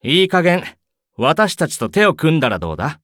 文件 文件历史 文件用途 全域文件用途 Ja_Bhan_fw_03.ogg （Ogg Vorbis声音文件，长度3.0秒，107 kbps，文件大小：40 KB） 源地址:游戏语音 文件历史 点击某个日期/时间查看对应时刻的文件。 日期/时间 缩略图 大小 用户 备注 当前 2018年5月25日 (五) 02:59 3.0秒 （40 KB） 地下城与勇士  （ 留言 | 贡献 ） 分类:巴恩·巴休特 分类:地下城与勇士 源地址:游戏语音 您不可以覆盖此文件。